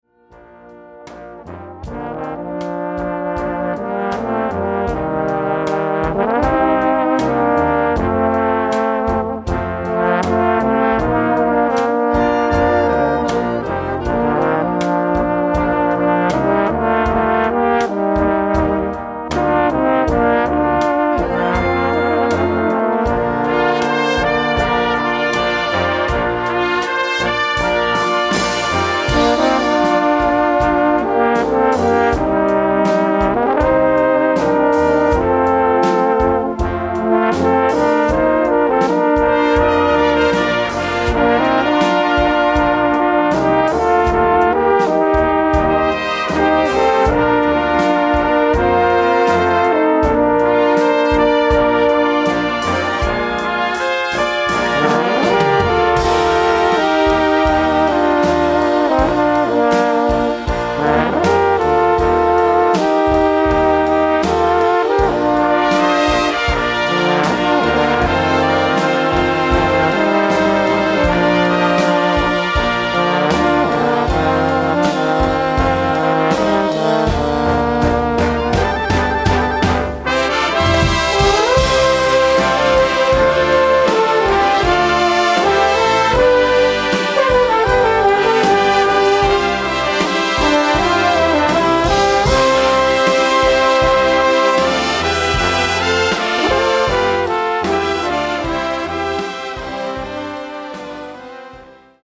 Solo für zwei Tenorhörner Schwierigkeitsgrad